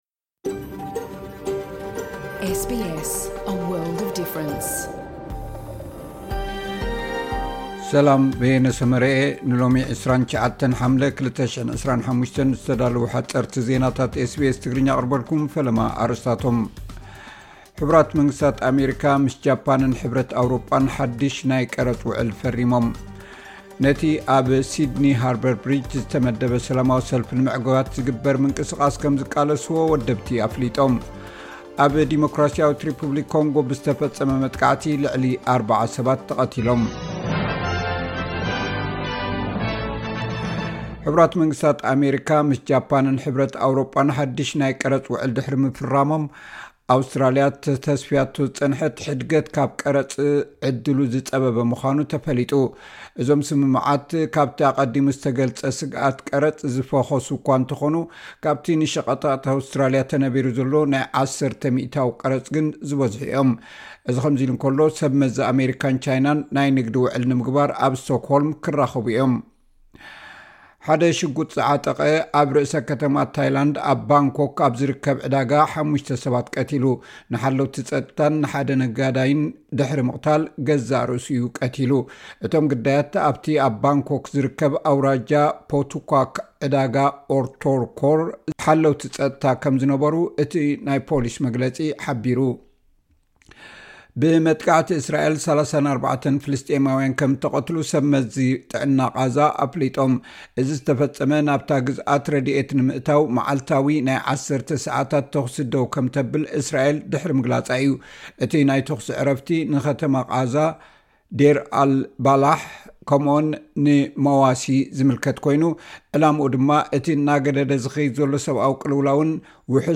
ሓጸርቲ ዜናታት ኤስ ቢ ኤስ ትግርኛ (29 ሓምለ 2025)